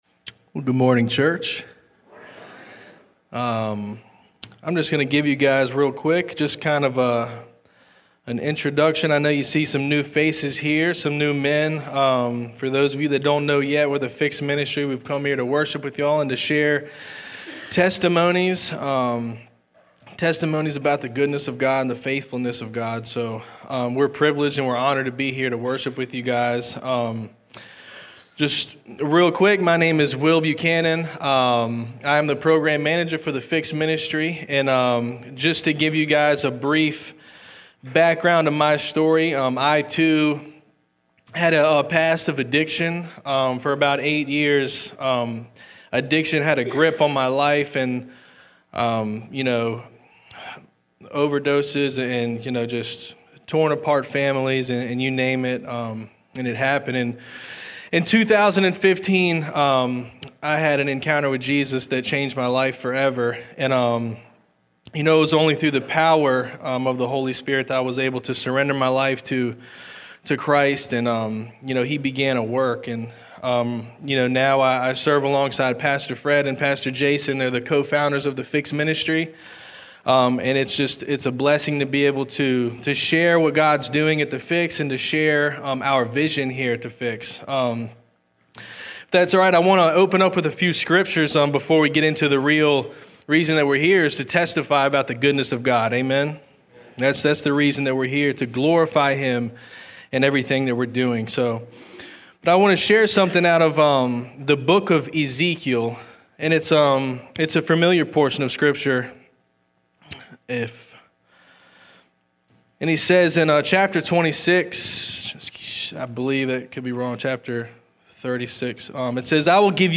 Passage: Luke 5:17-26 Service Type: Sunday Service